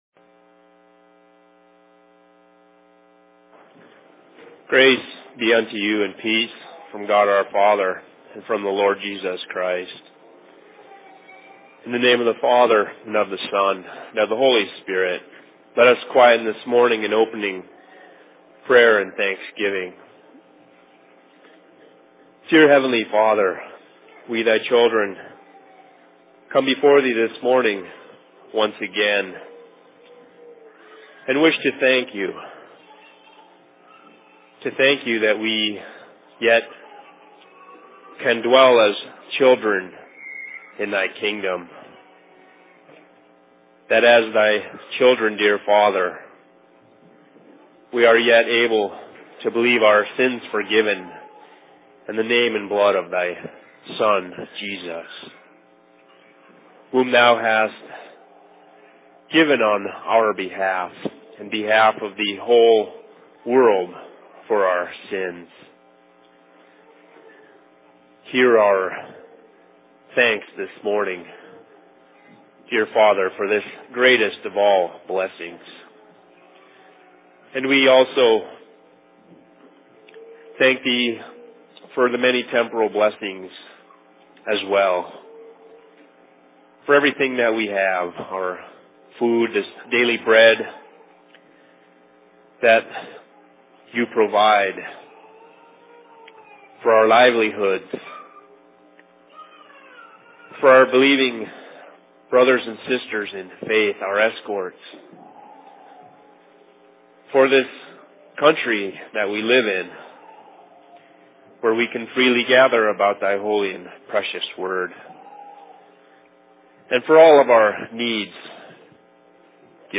Sermon in Seattle 10.10.2010
Location: LLC Seattle